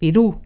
Click the button below to hear the pronunciation of the word